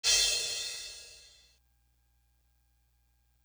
Crashes & Cymbals
Tick Tock Hat Crash.wav